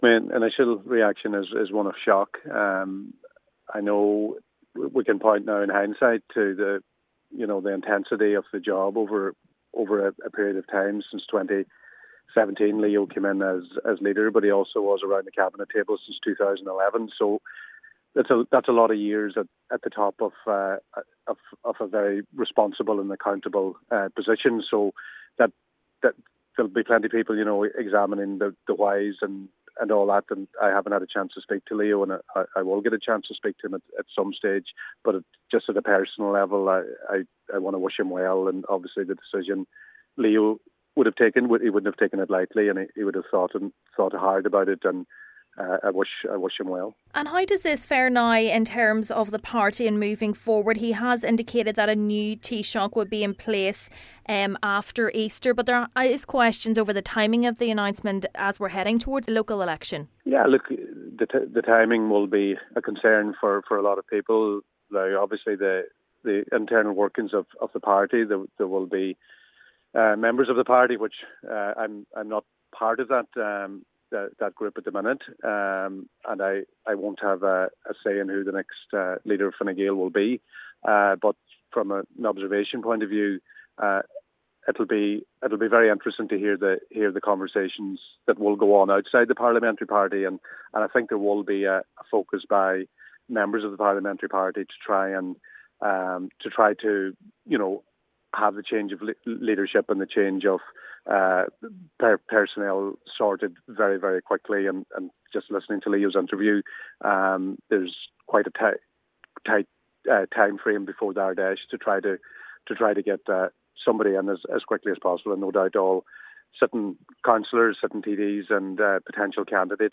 Deputy McHugh is not contesting the next general election but he says those running in the upcoming local and European elections will hope a new leader is announced quickly: